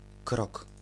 Voiced alveolar trill
Polish[25] krok
[krɔk] 'step' Usually realized as [ɾ]. See Polish phonology.